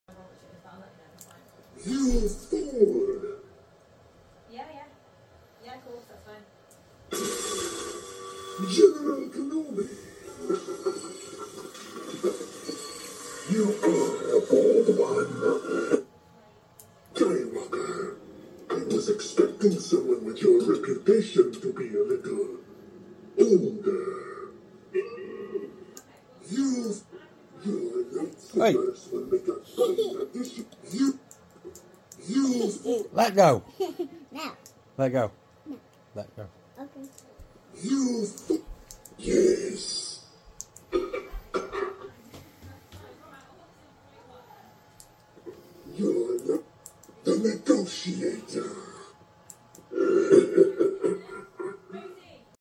testing out the soundboard ready for the glove I'm impressed I found a speaker to fit in his head so it sounds as though he's talking